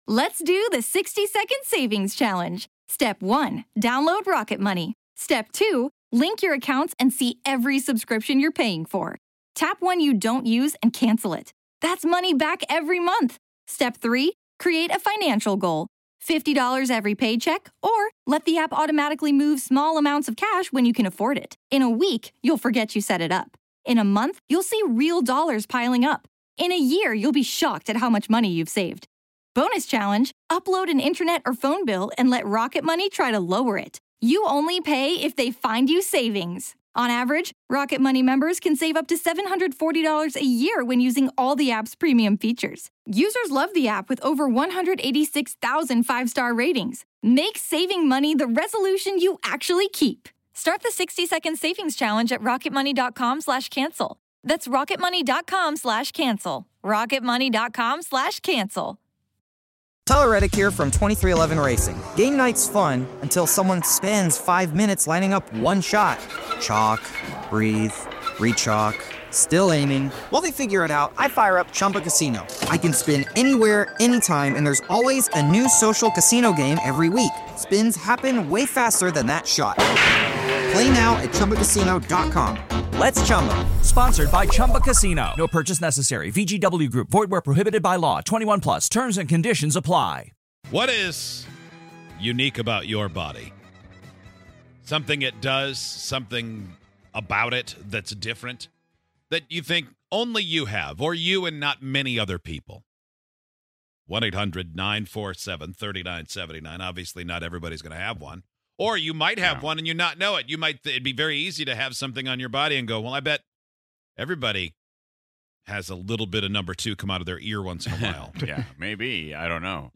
While one caller grew an additional ovary, another never has to experience sinus infections!